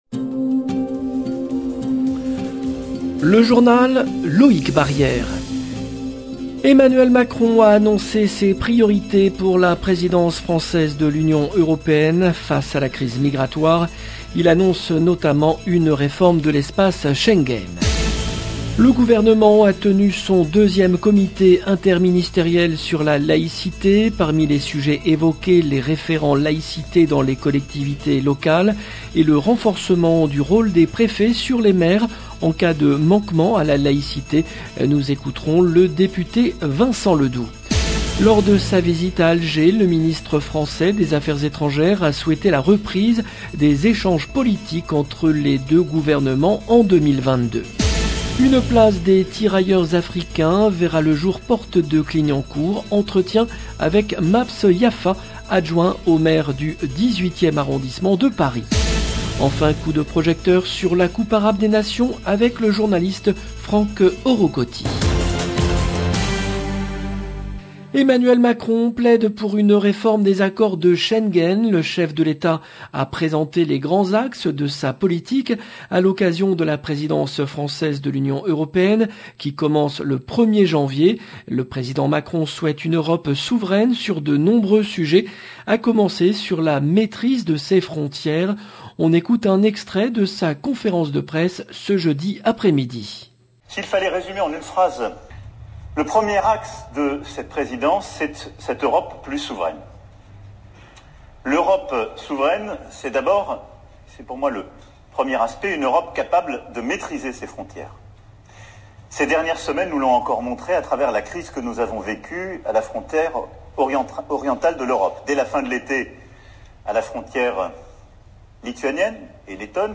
Entretien avec le député Vincent Ledoux Lors de sa visite à Alger, le ministre français des AE a souhaité la reprise des échanges politiques entre les deux gouvernements en 2022. Une place des tirailleurs africains verra le jour Porte de Clignancourt.